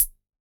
RDM_TapeB_SR88-ClHat.wav